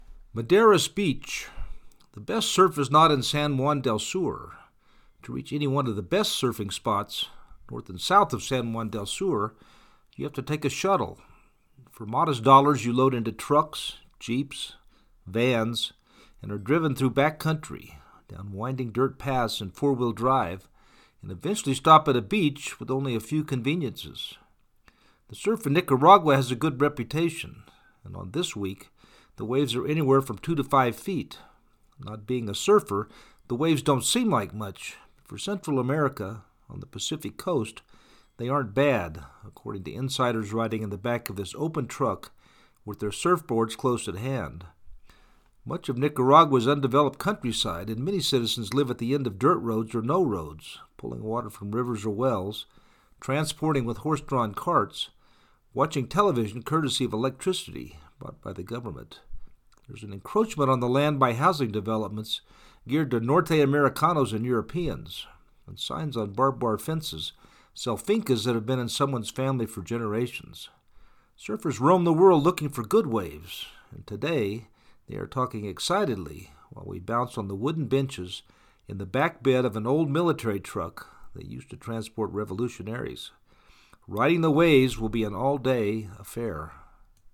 Surfers roam the world looking for good waves, and, today, they are talking excitedly while we bounce on the wooden benches in the back bed of the old military truck that used to transport revolutionaries..
maderas-beach-6.mp3